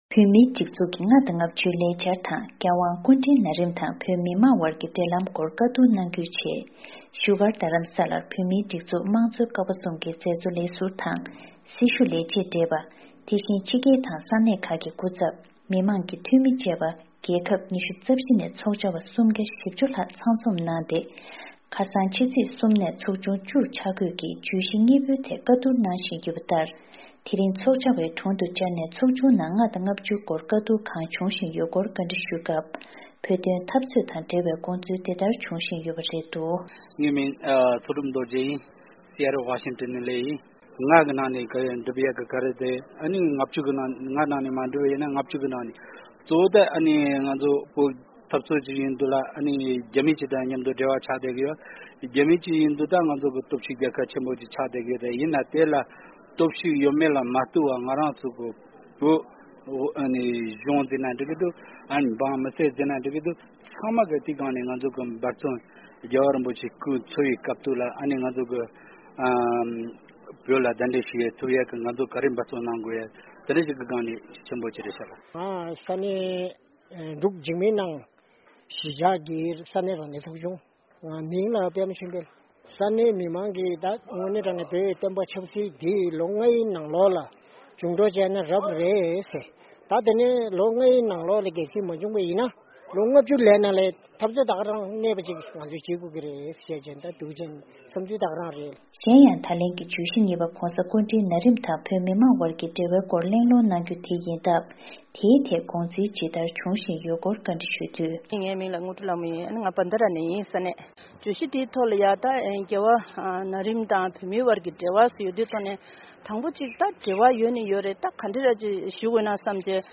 ཚོགས་བཅར་བ་ཁག་ཅིག་ལ་བཅར་འདྲི་ཞུས་པའི་གནས་ཚུལ་དེ་གསན་རོགས་གནང་།